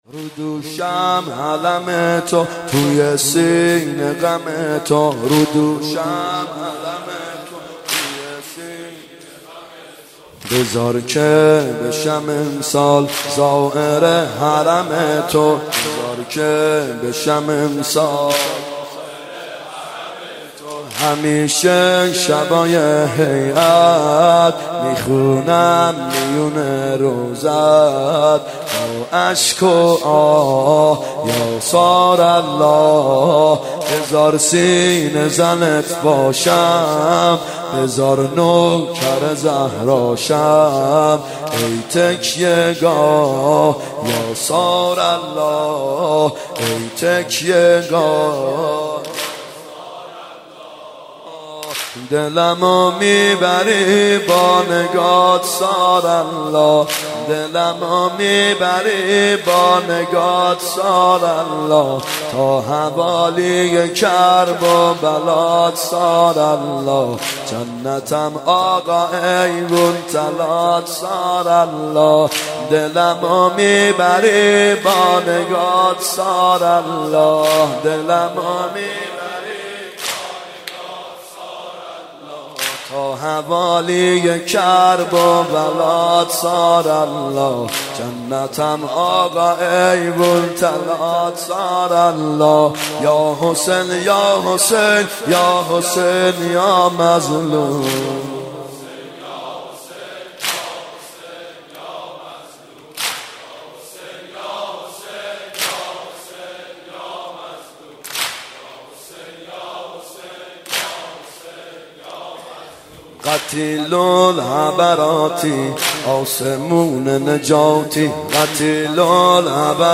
محرم 92 شب نهم واحد (رو دوشم علم تو توی سینه غم تو
محرم 92 ( هیأت یامهدی عج)